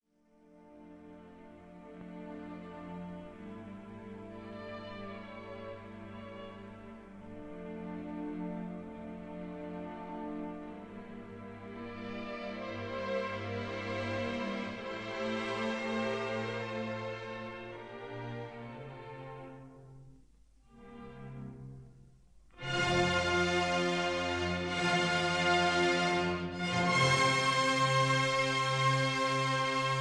This is a 1960 stereo recording